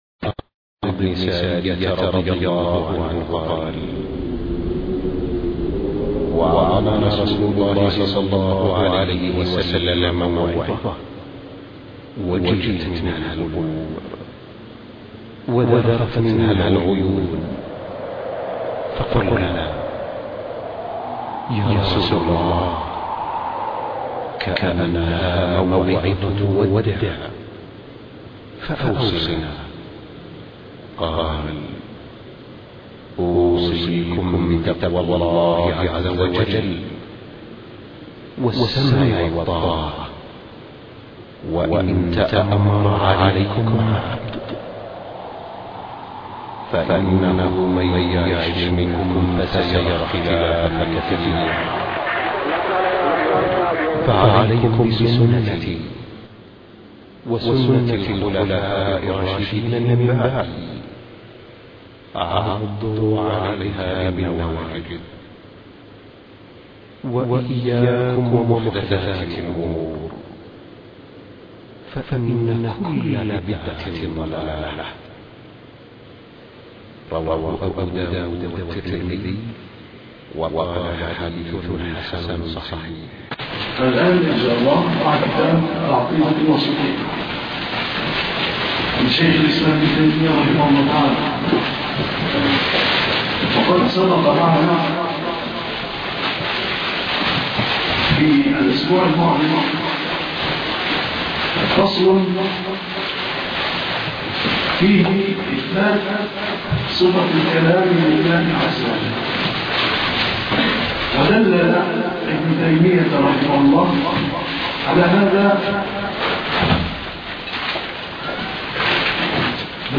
الدرس الثالث والعشرون ( شرح العقيدة الواسطية )